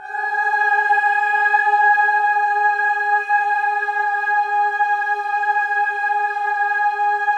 VOWEL MV13-L.wav